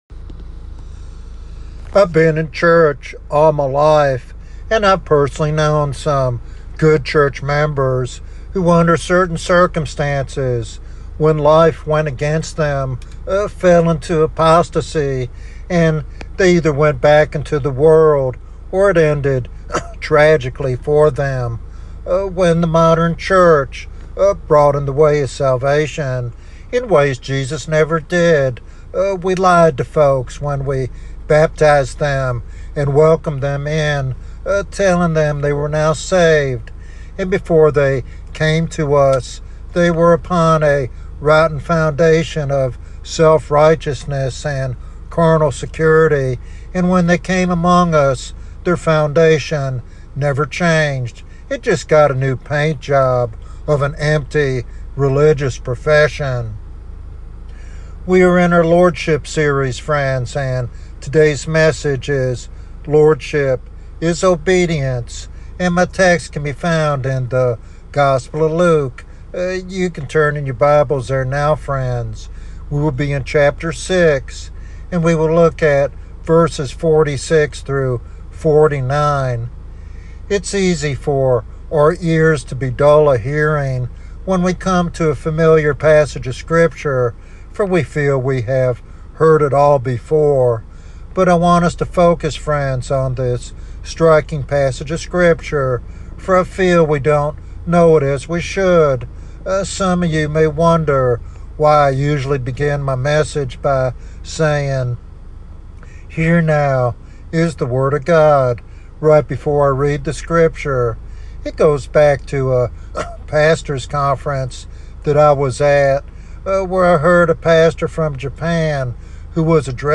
Sermon Outline